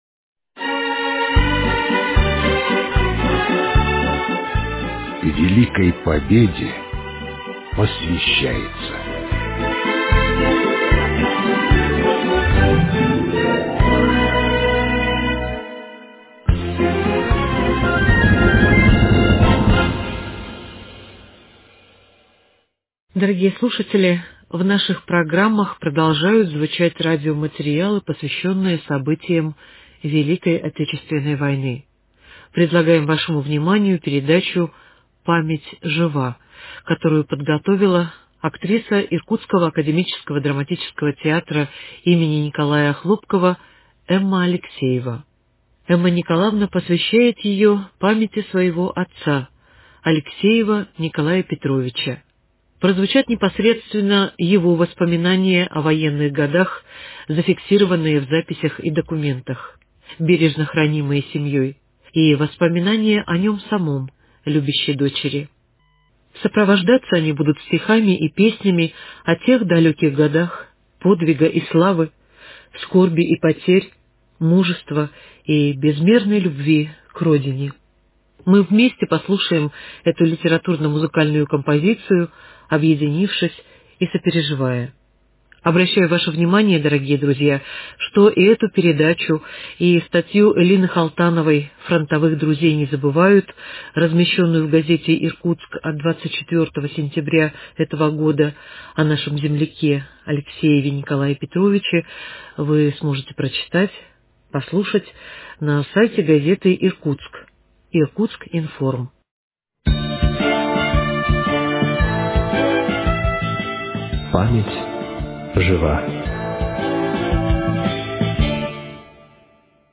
Сопровождаться они будут стихами и песнями о тех далеких годах подвига и славы, скорби и потерь, мужества и безмерной любви к родине… Мы вместе послушаем эту литературно-музыкальную композицию, объединившись и сопереживая…